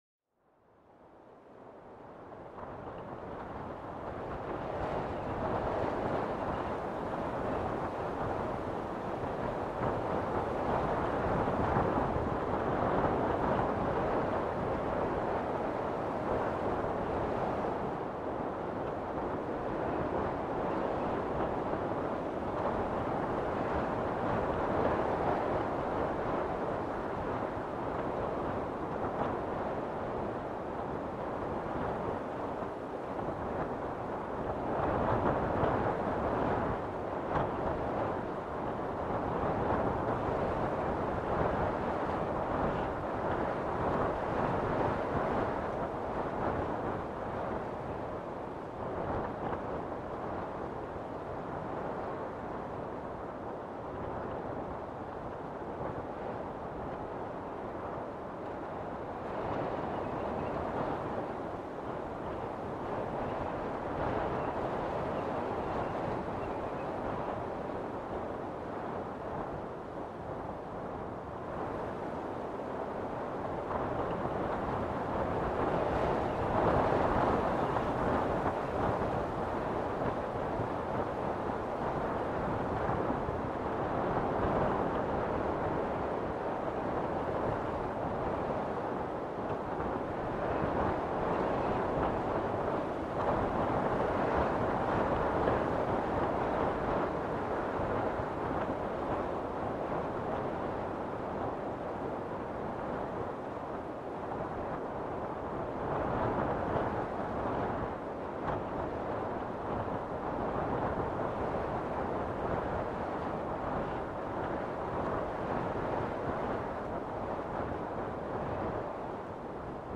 Plongez dans l'univers envoûtant du vent qui caresse les sommets des arbres et danse à travers les feuilles, un véritable chant de la nature.